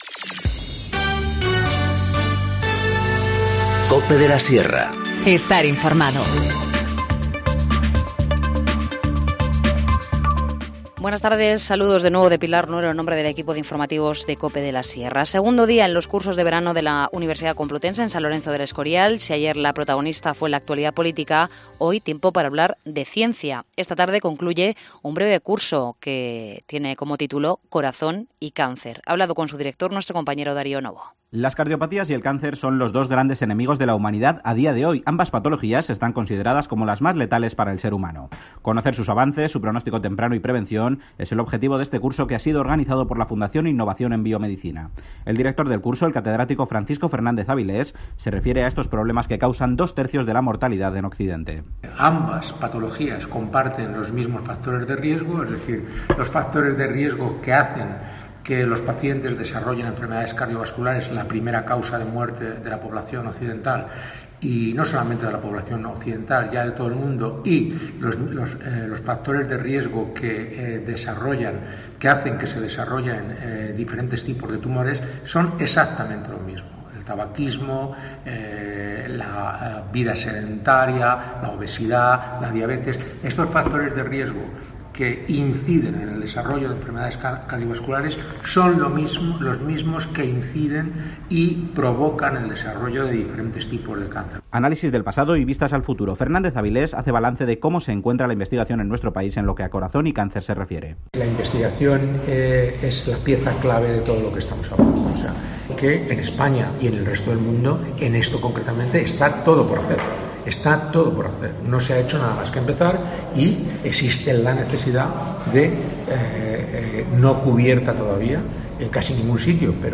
Informativo Mediodía 2 julio 14:50h